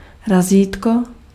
Ääntäminen
IPA : /stæmp/